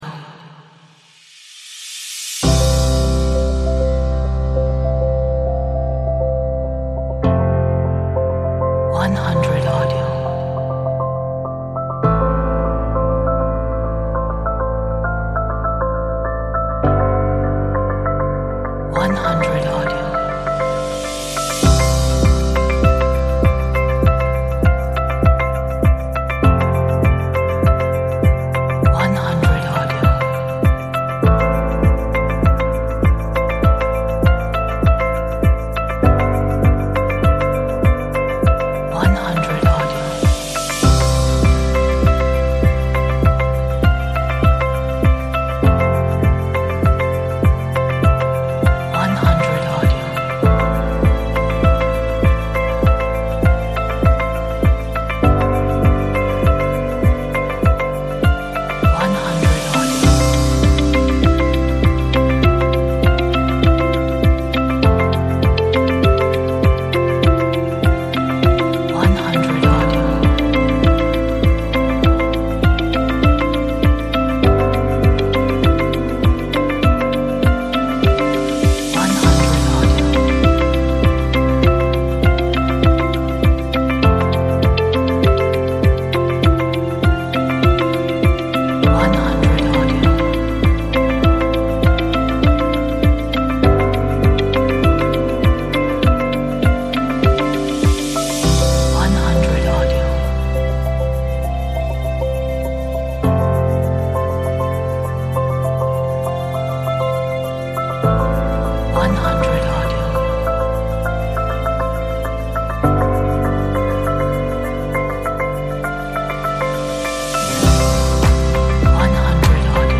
Calm and inspiring beautiful background for your artwork.